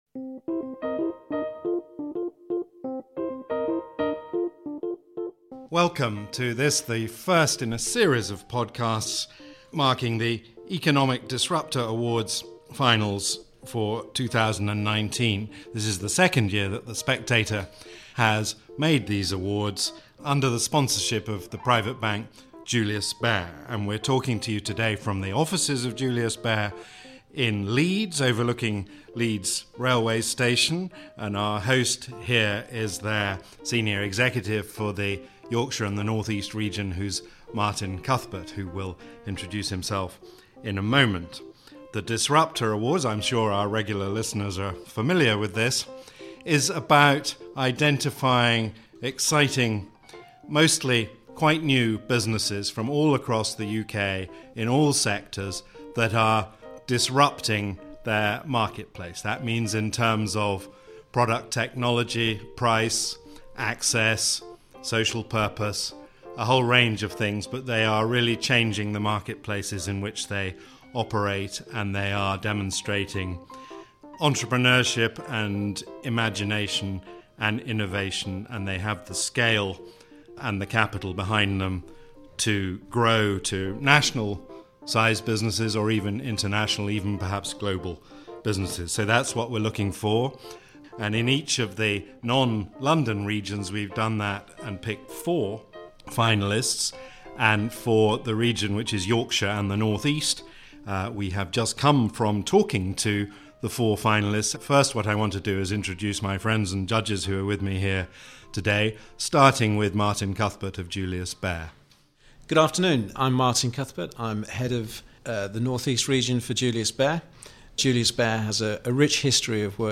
News Commentary, News, Daily News, Society & Culture